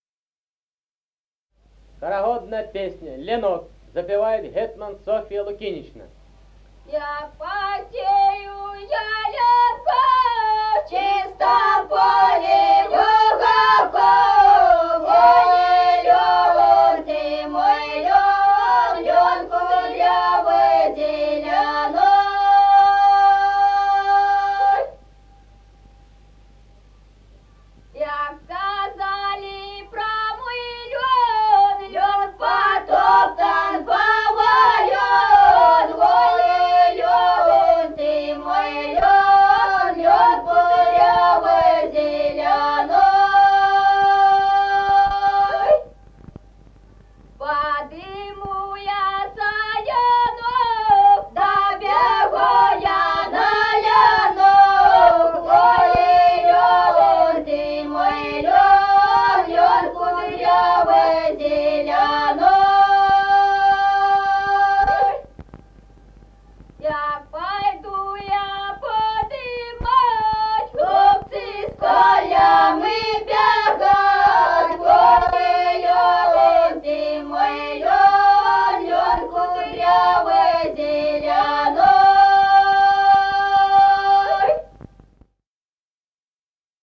Музыкальный фольклор села Мишковка «Як посею я ленку», хороводная.